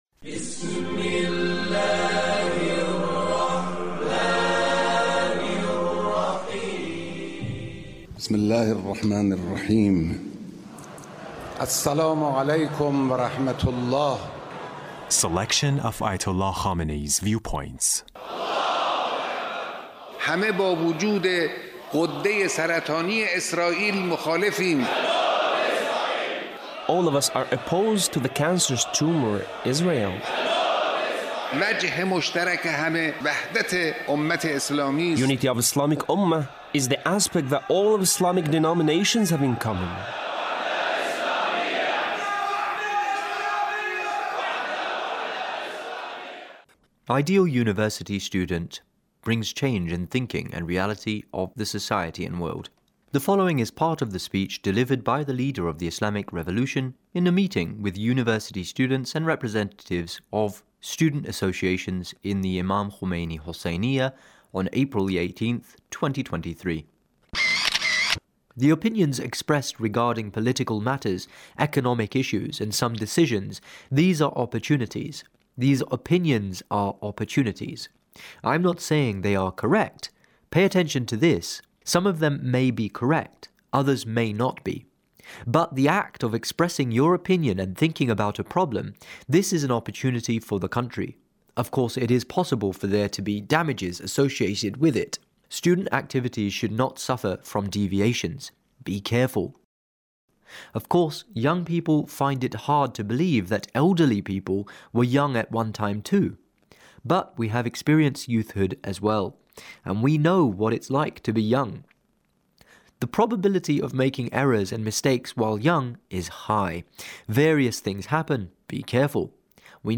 Leader's Speech (1707)
Leader's Speech with University Student